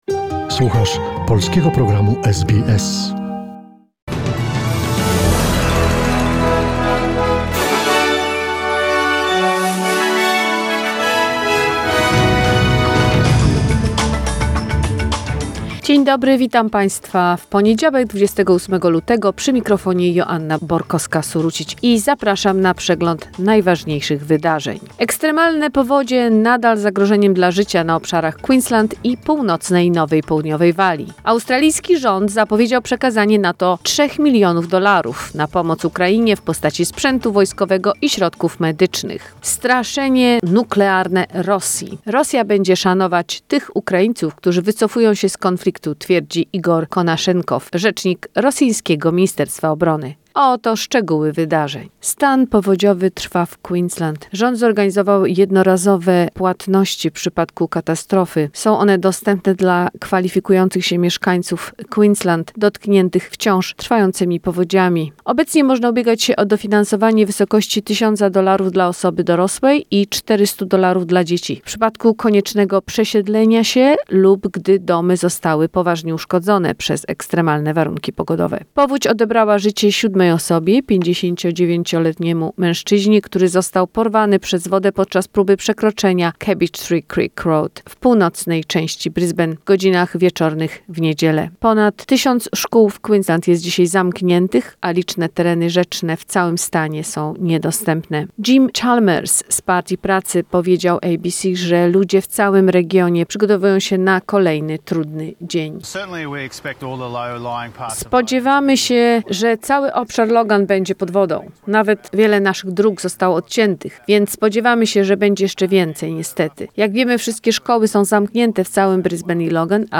SBS News in Polish, 28 February 2021